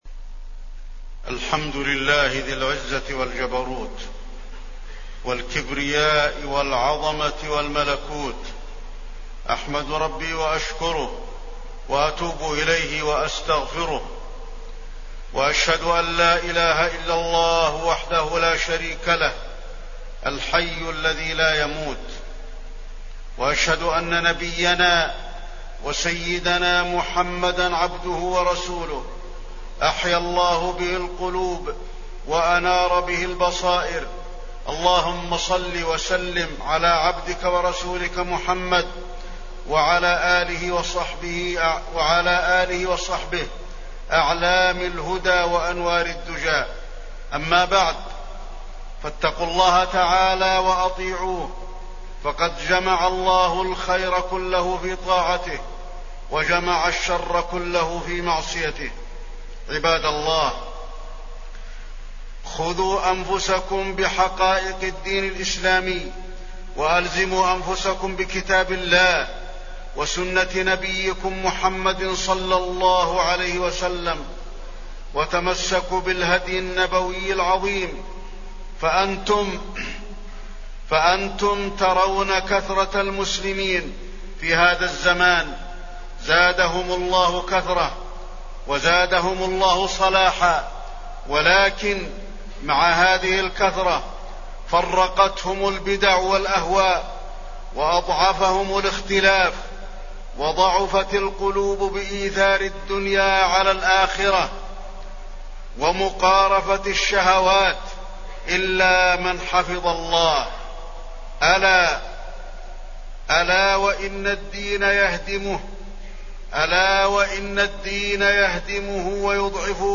تاريخ النشر ٥ جمادى الآخرة ١٤٣٠ هـ المكان: المسجد النبوي الشيخ: فضيلة الشيخ د. علي بن عبدالرحمن الحذيفي فضيلة الشيخ د. علي بن عبدالرحمن الحذيفي البدع